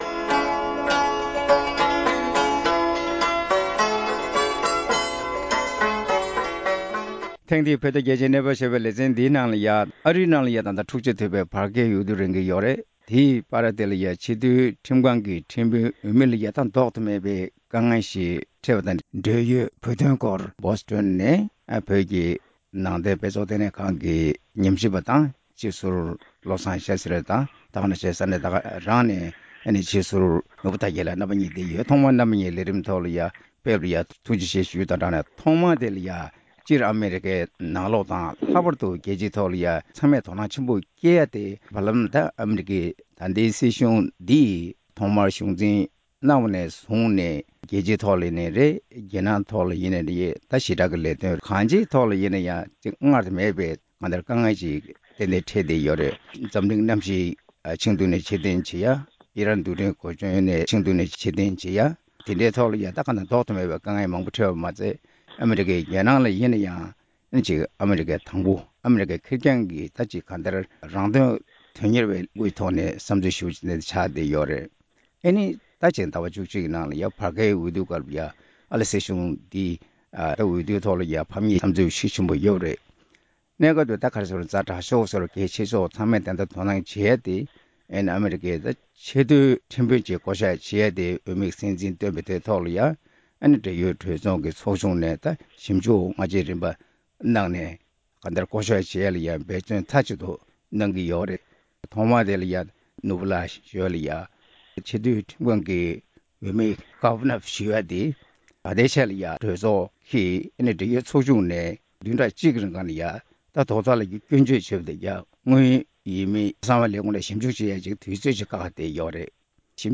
ཨ་རི་ནང་གི་བར་བརྒལ་འོས་བསྡུ་དང་ཆེས་མཐོའི་ཁྲིམས་ཁང་གི་ཁྲིམས་དཔོན་འོས་མིའི་དཀའ་ངལ་ཐད་གླེང་མོལ།